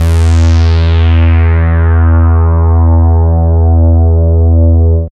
75.05 BASS.wav